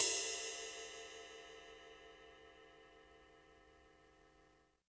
Index of /90_sSampleCDs/Roland L-CDX-01/CYM_Rides 1/CYM_Ride menu